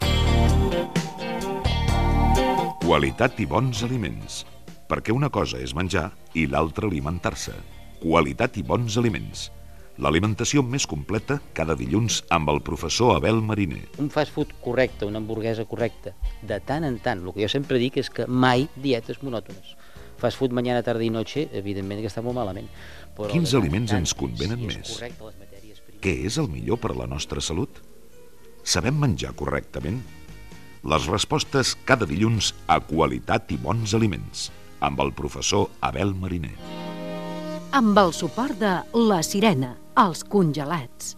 FM
Programa presentat per Josep Cuní.